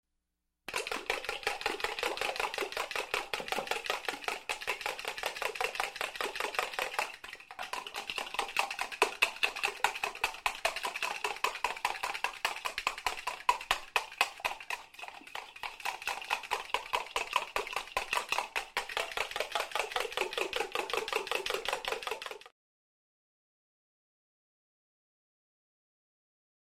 На этой странице собраны натуральные звуки приготовления яичницы: от разбивания скорлупы до аппетитного шипения на сковороде.
Звук взбивания яиц венчиком в миске